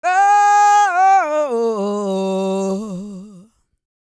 E-CROON 205.wav